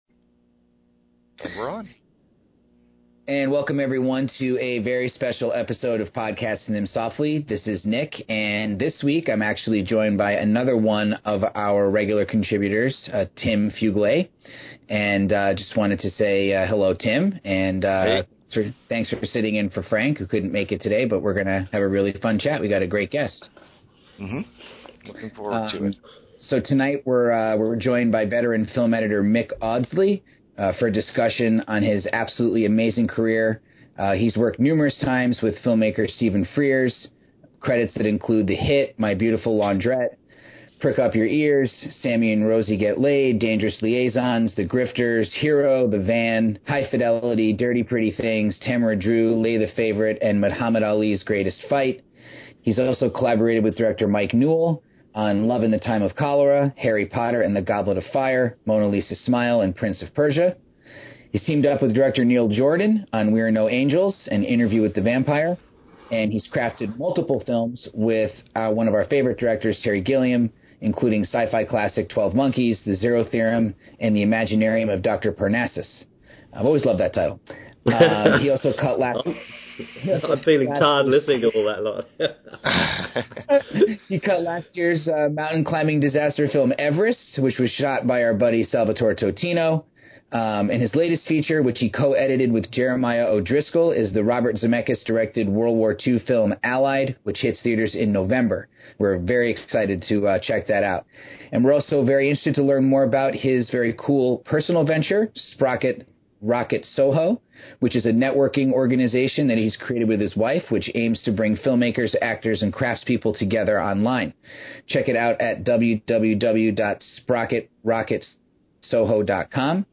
Right off the bat, I really wanted to give you a cool video interview.
Luckily for both of us, I had my trusty digital recorder silently working at the same time.